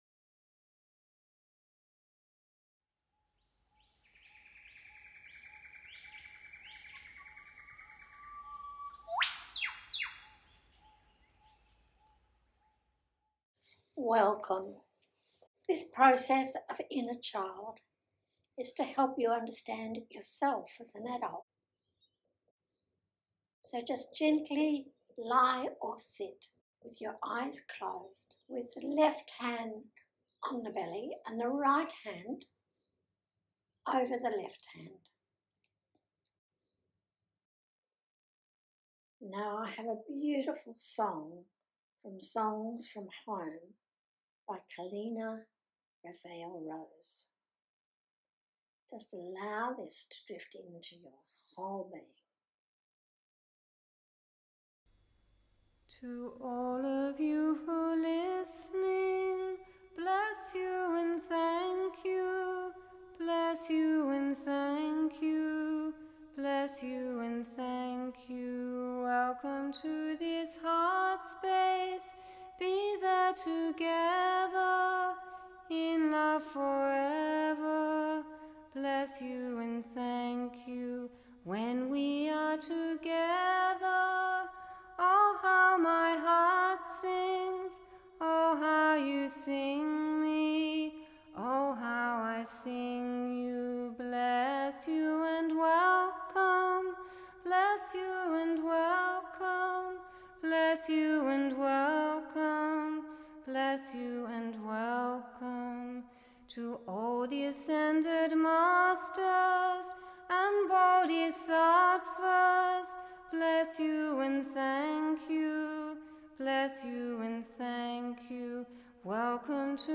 Inner Child Breathwork Meditation